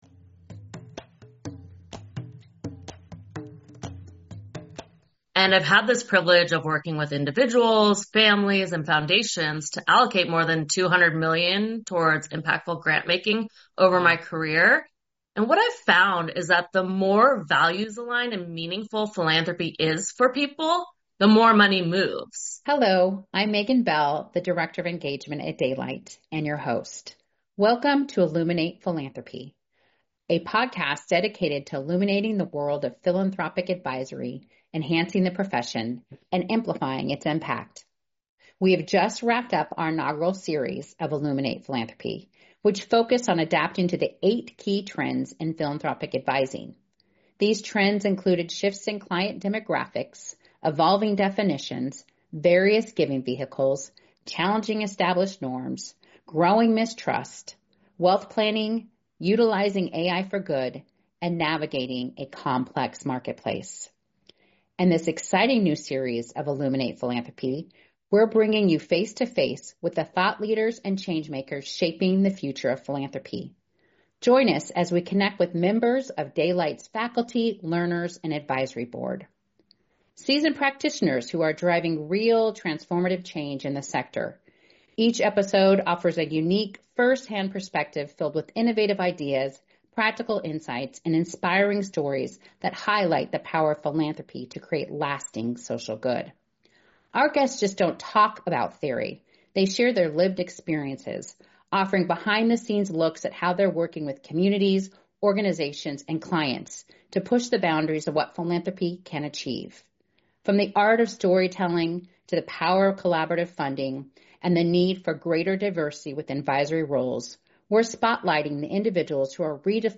Ep10 Illuminate Philanthropy — An interview